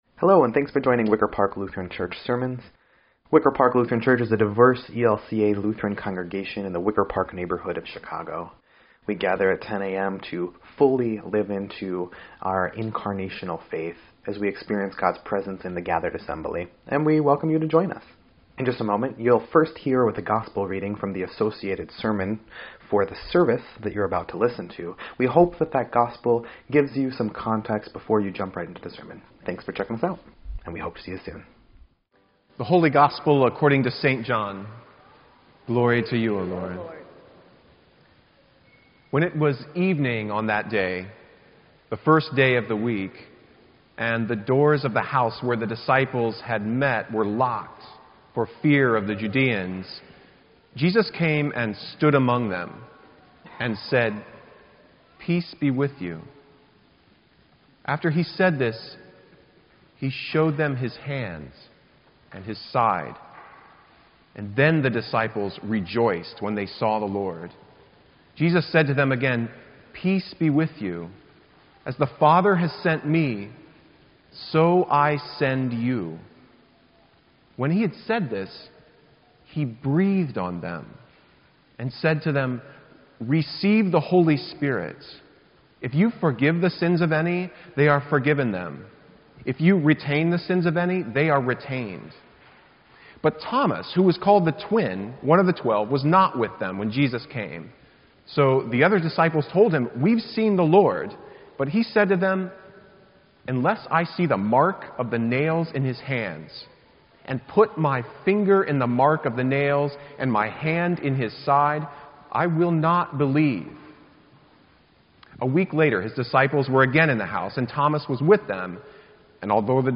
Sermon_4_23_17_EDIT.mp3